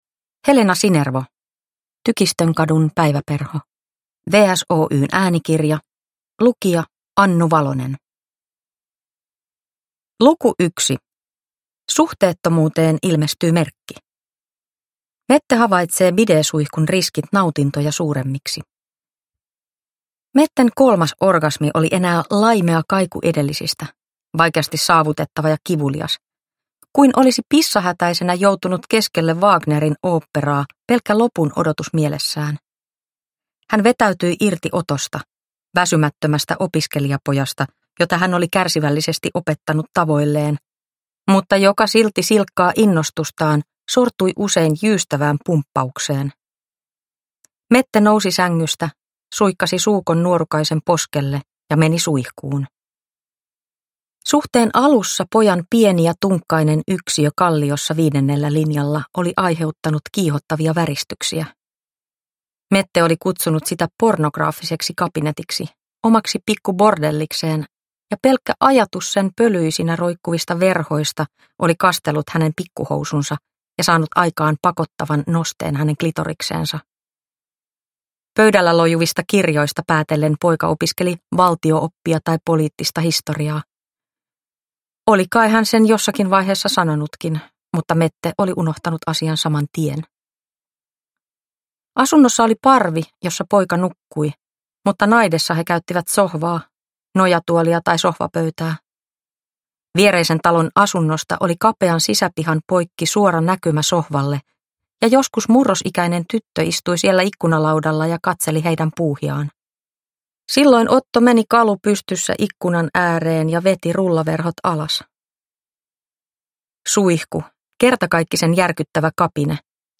Tykistönkadun päiväperho (ljudbok) av Helena Sinervo